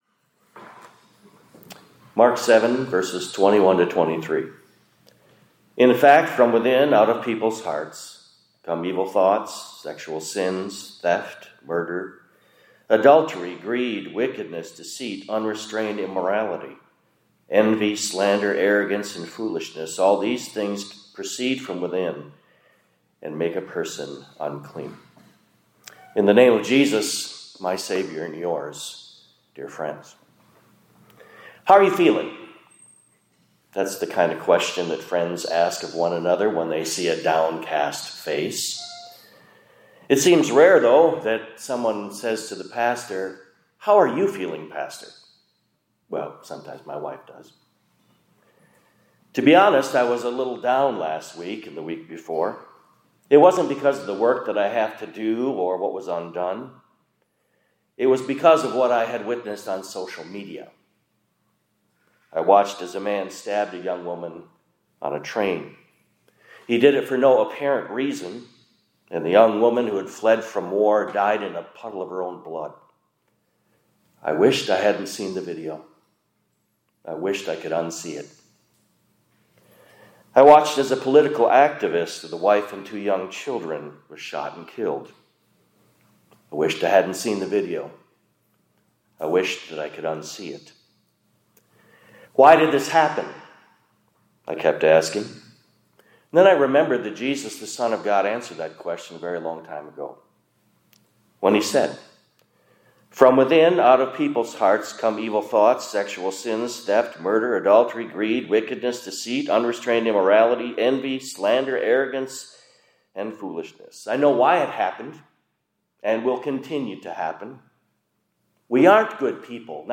2025-09-23 ILC Chapel — The Heart of Man vs.…